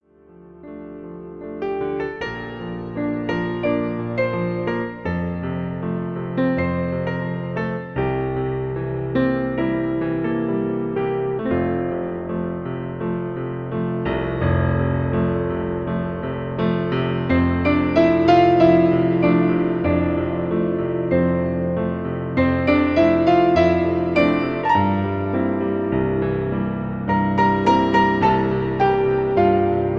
Piano Version